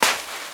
High Quality Footsteps
STEPS Sand, Walk 16.wav